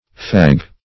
Fag \Fag\ (f[a^]g) n.